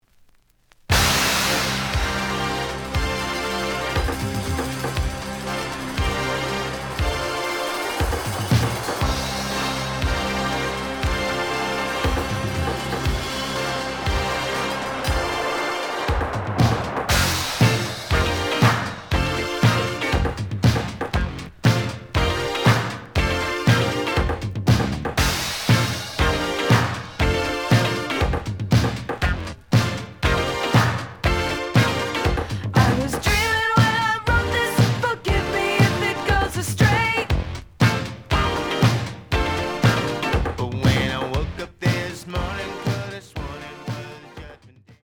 The audio sample is recorded from the actual item.
●Format: 7 inch
●Genre: Funk, 80's / 90's Funk